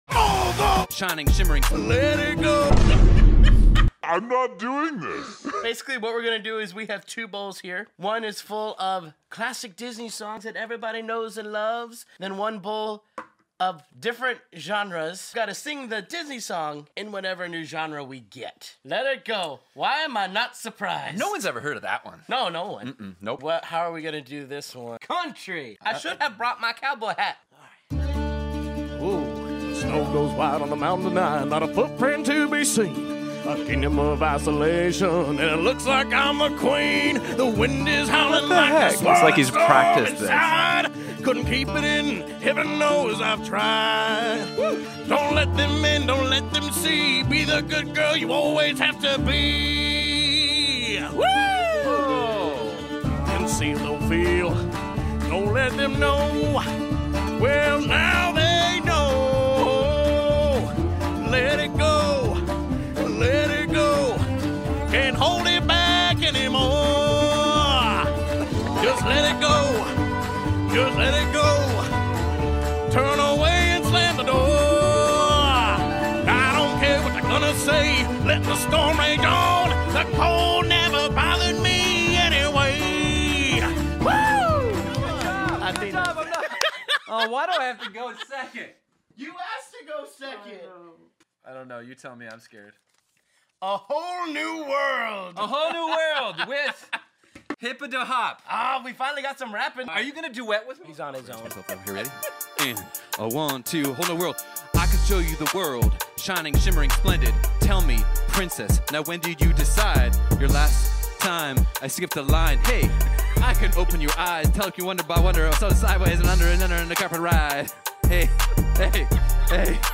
into everything from reggae to metal!